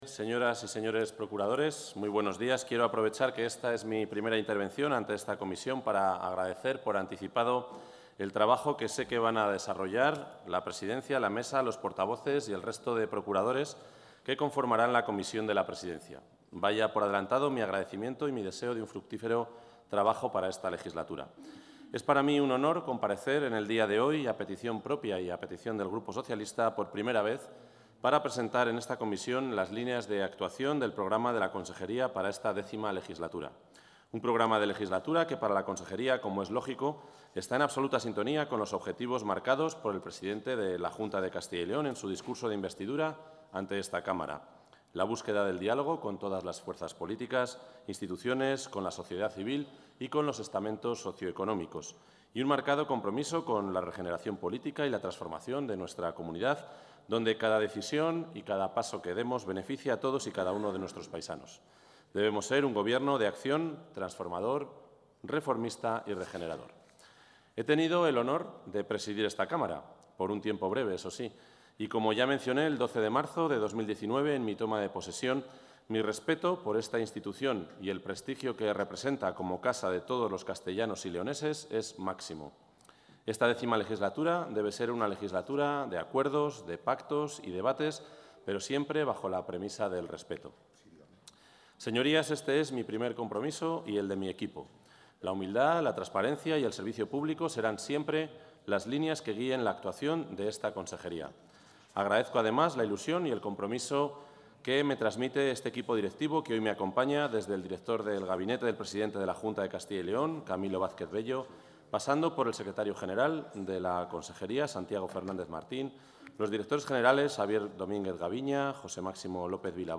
El consejero de la Presidencia, Ángel Ibáñez, ha comparecido esta mañana en la Comisión de la Presidencia de las...
Comparecencia.